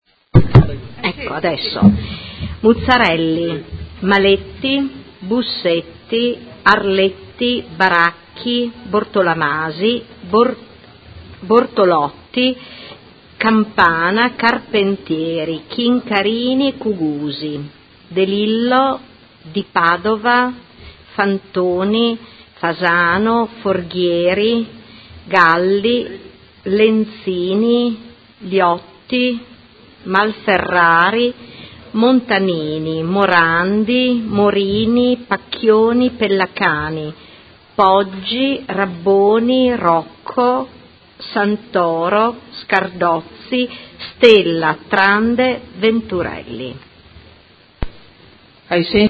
Seduta del 23/11/2007 Appello
Vice Segretaria Generale